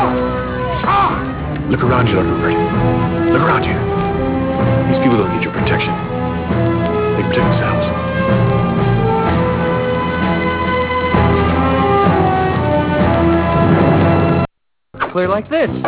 deepvoice.wav